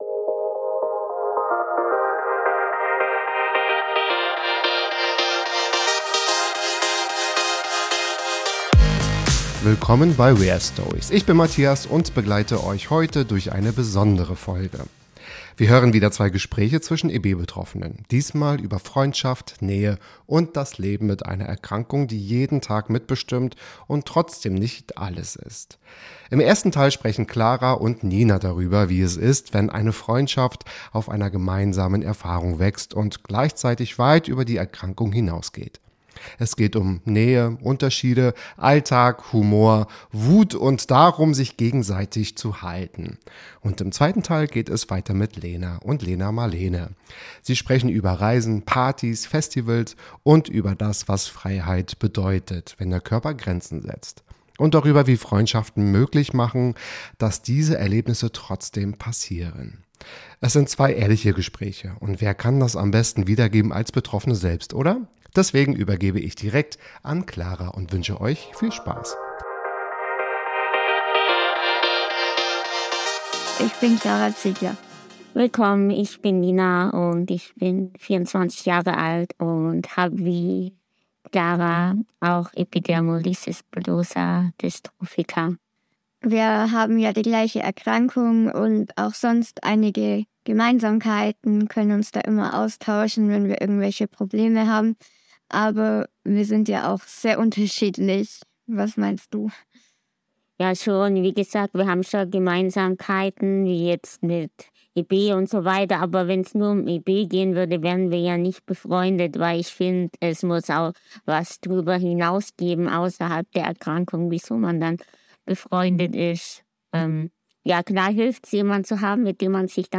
In dieser Folge hören wir wieder zwei Gespräche zwischen EB-Betroffenen.